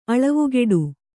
♪ aḷavugeḍu